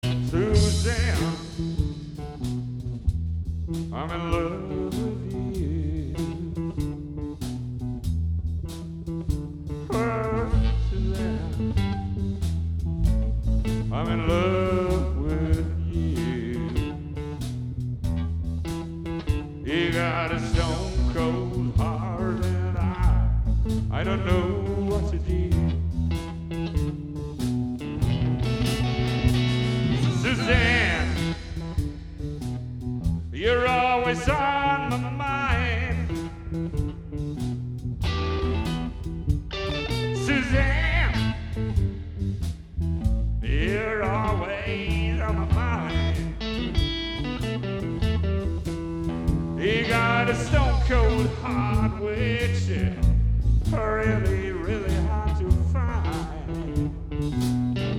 (Proberaumaufnahme)